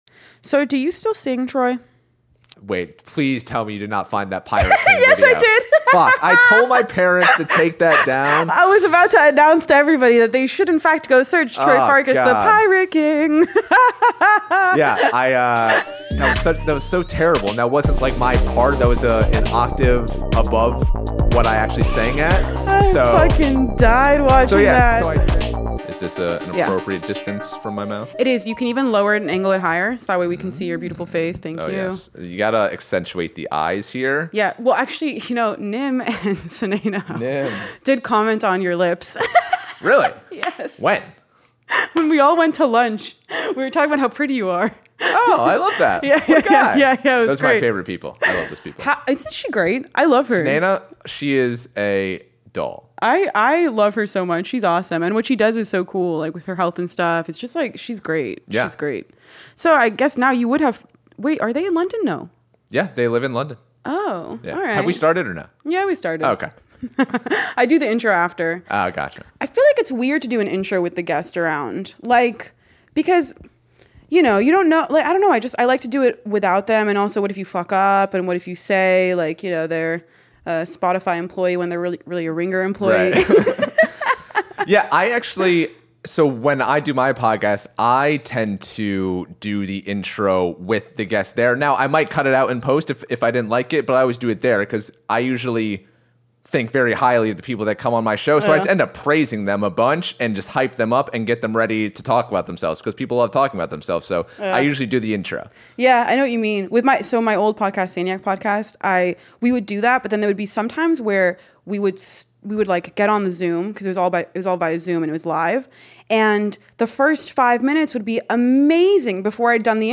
Tune in every week for sharp takes and interviews.